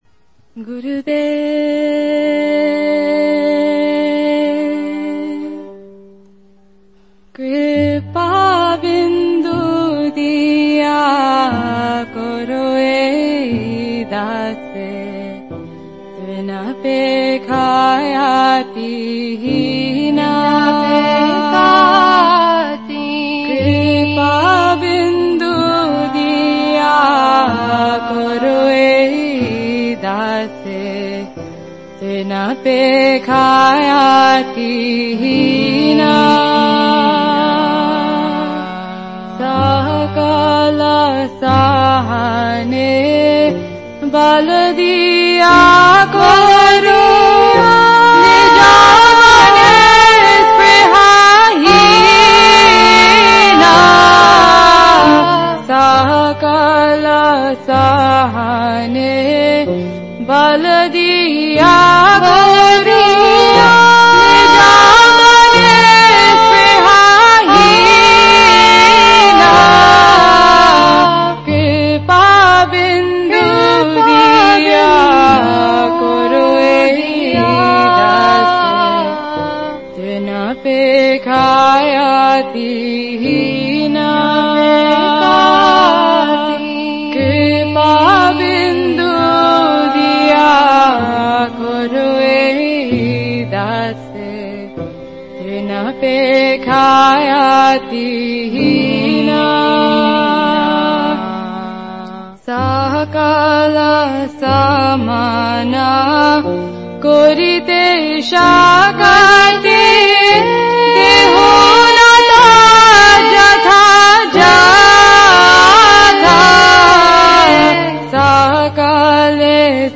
Bhajans and Kirtan
2016 Festival of the Holyname